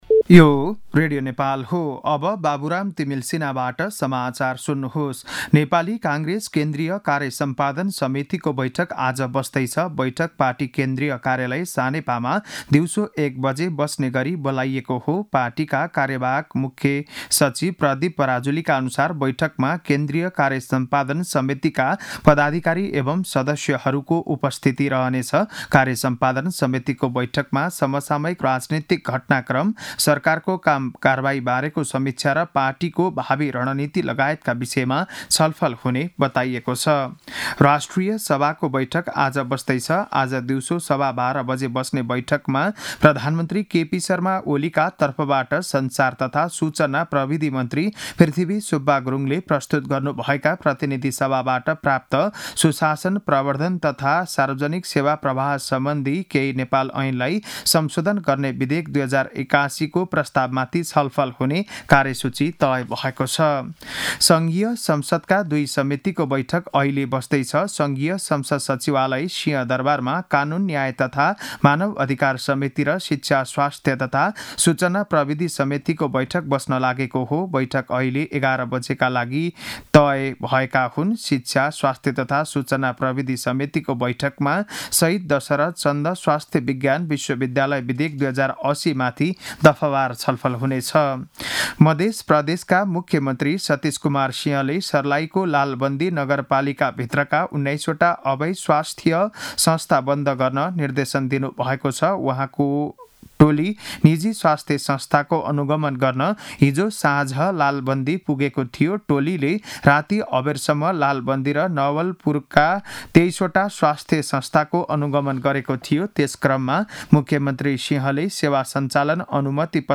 बिहान ११ बजेको नेपाली समाचार : १० चैत , २०८१